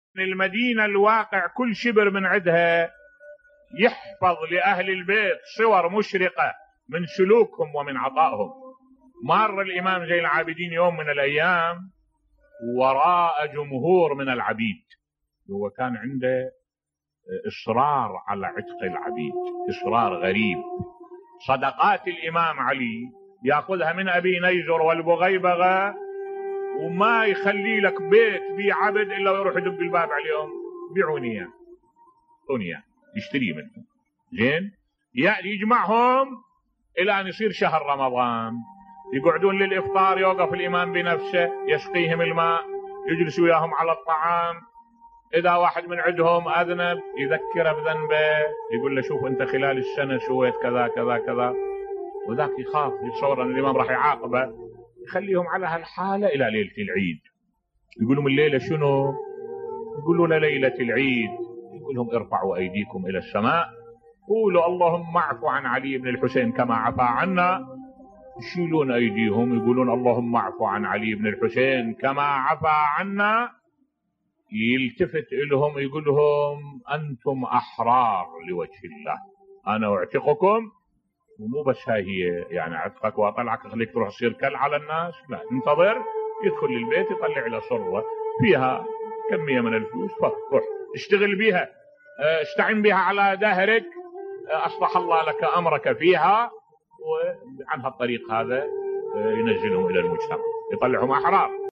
ملف صوتی كيف كان الامام زين العابدين (ع) يعتق العبيد بصوت الشيخ الدكتور أحمد الوائلي